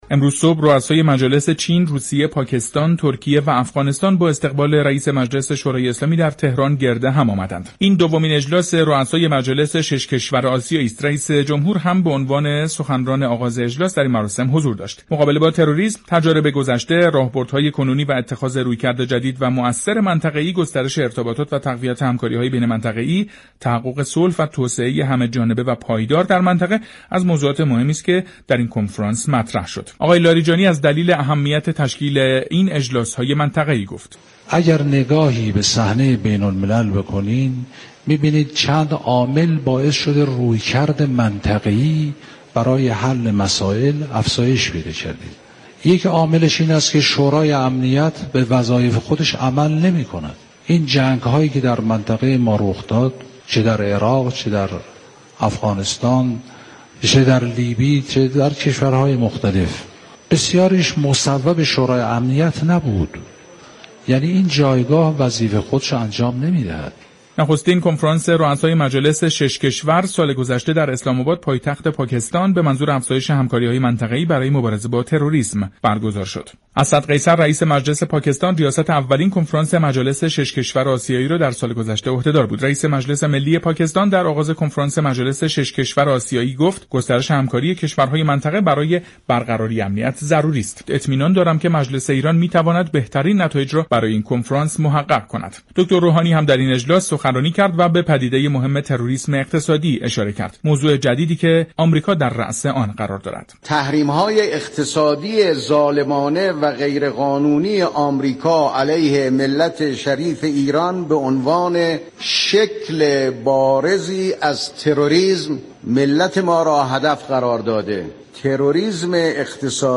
ابراهیم رحیم پور معاون سابق آسیا-اقیانوسیه وزارت خارجه در برنامه جهان سیاست رادیو ایران